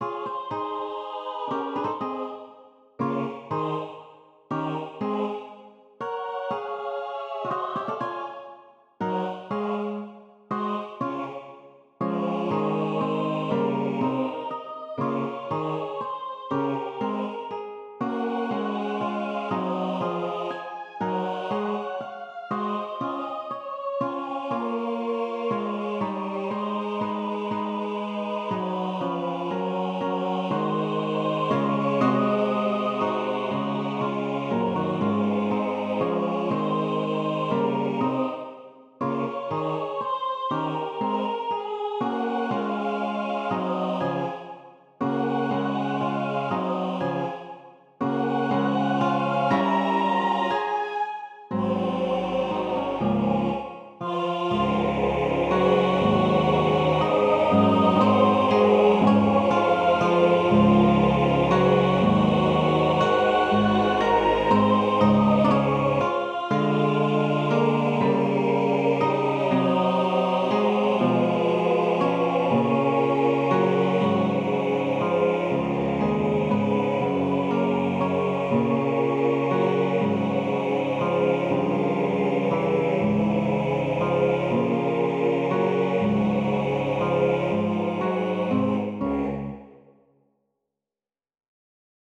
Key: C-sharp minor)
SATB + GUITAR: